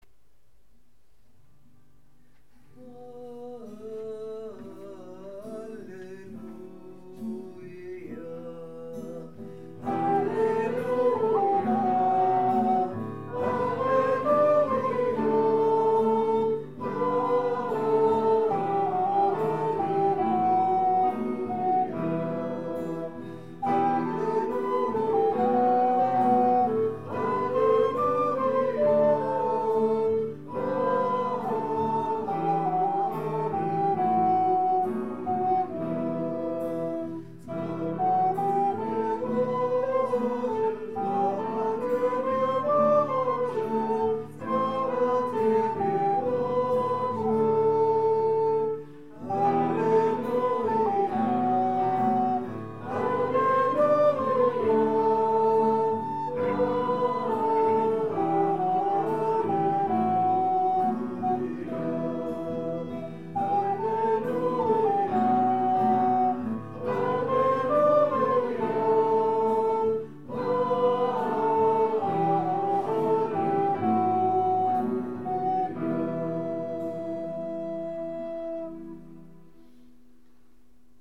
Pregària de Taizé
Capella de les Concepcionistes de Sant Josep - Diumenge 30 de novembre de 2014